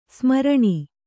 ♪ smaraṇi